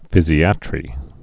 (fĭzē-ătrē, fĭ-zīə-trē)